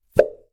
Звук прилипающей присоски